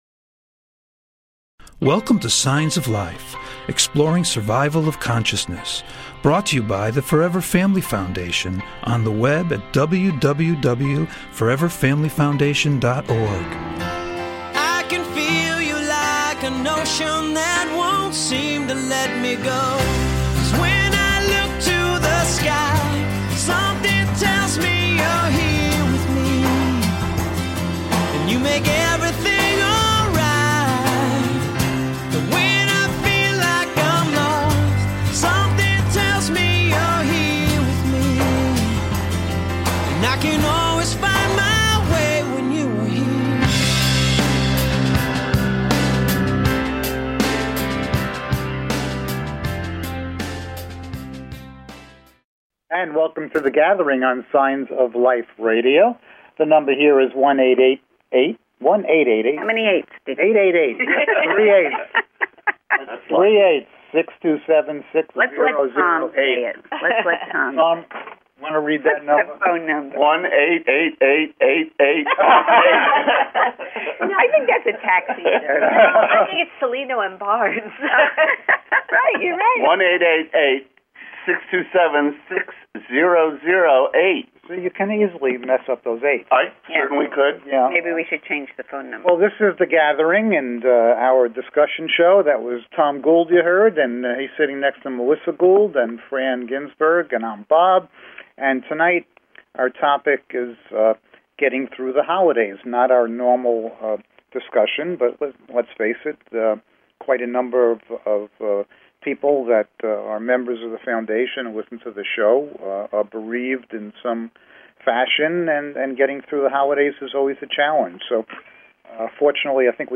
Signs of Life - The Gathering Discussion Show